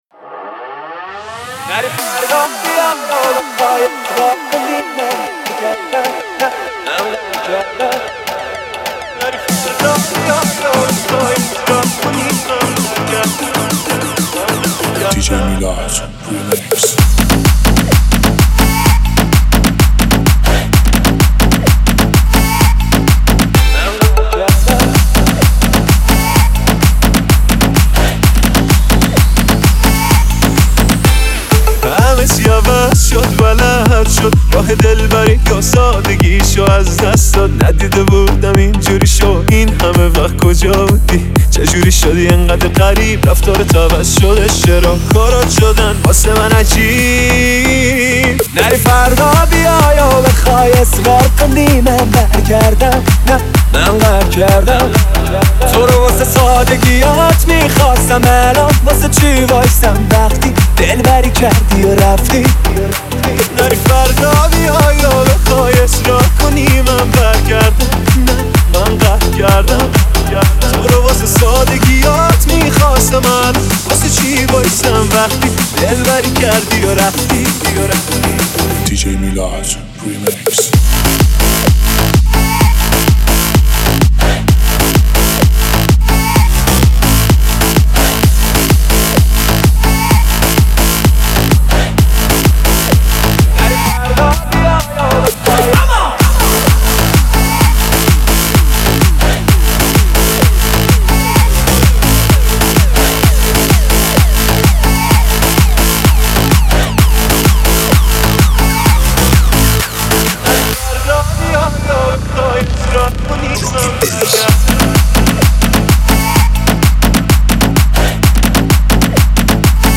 دانلود ریمیکس با کیفیت 320